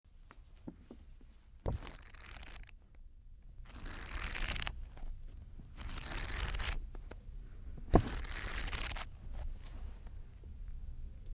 Download Book sound effect for free.
Book